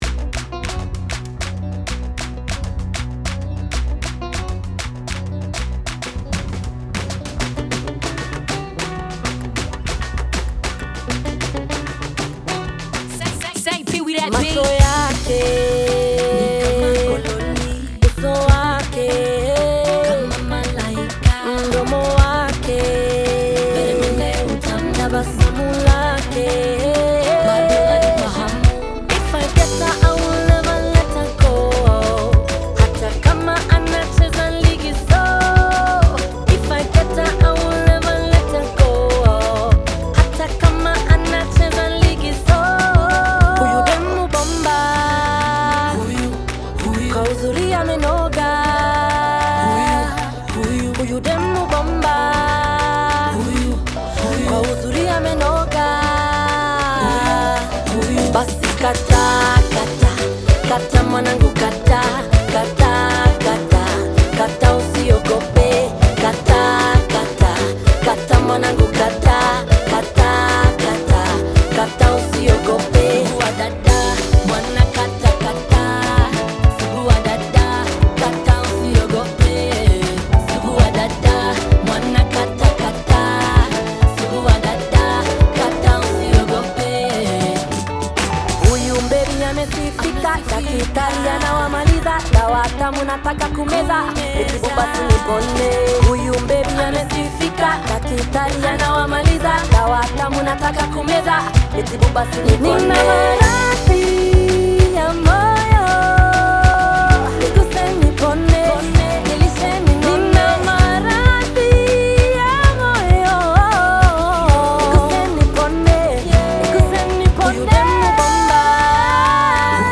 a new spin to an old Kenyan Chakacha tune